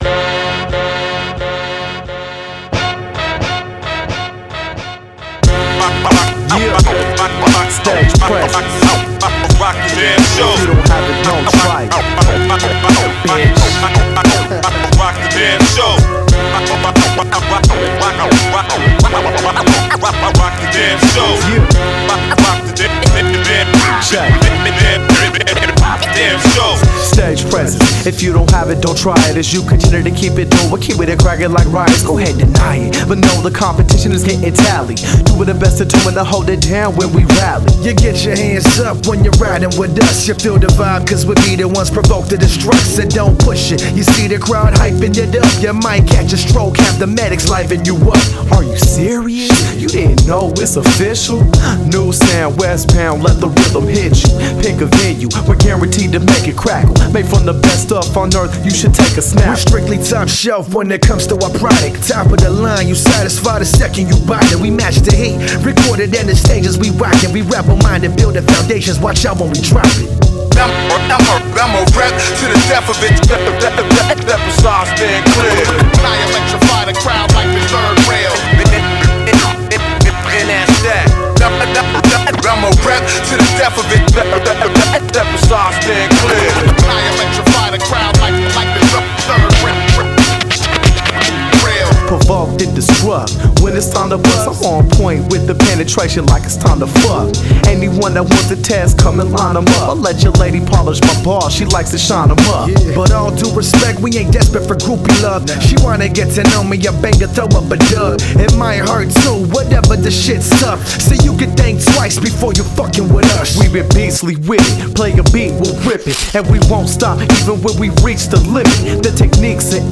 The tempos on the tracks are ridiculous!